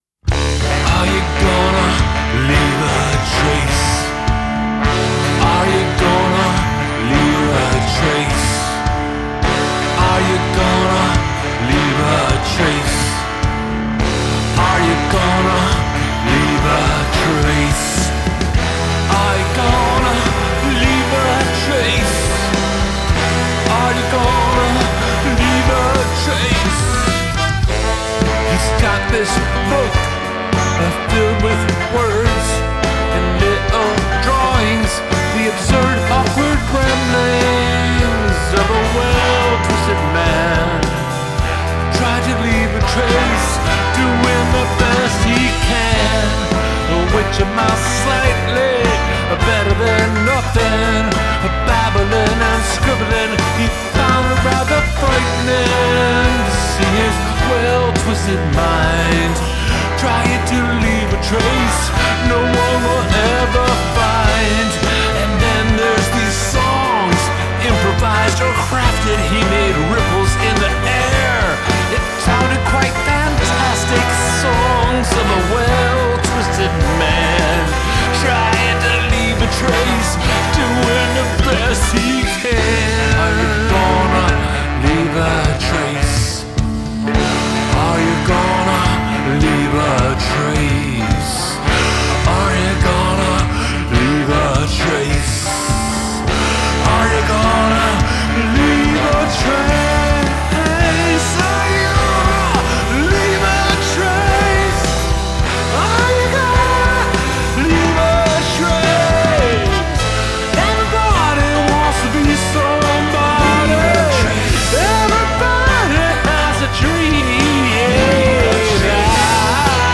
Written and recorded in 1996, mangobananas studios, Ithaca, NY.
loops from some EBA improv jams
acoustic guitars [DADGBE], electric guitars [DADGBE], piano
drums, percussion
trumpet
trombone
tenor saxophone
baritone saxophone
backing vocals